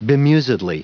Prononciation du mot bemusedly en anglais (fichier audio)
Prononciation du mot : bemusedly